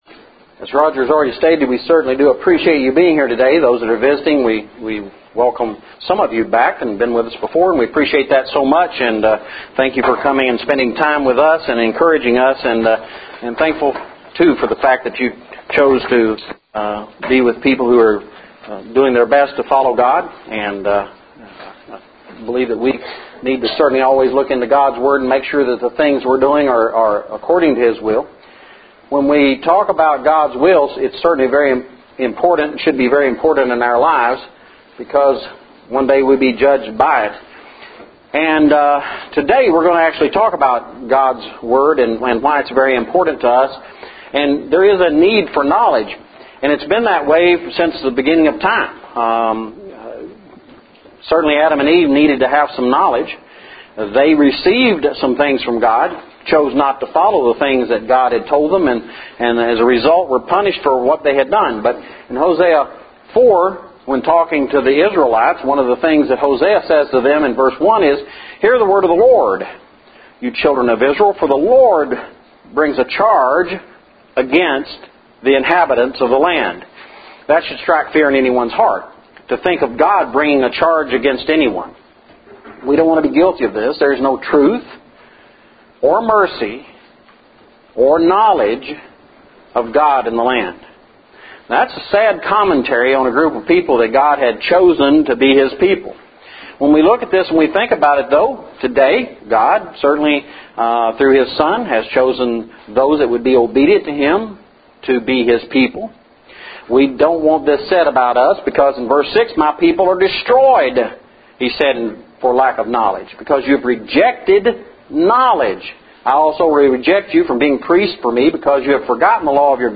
The Need for Knowledge Lesson – 06/24/12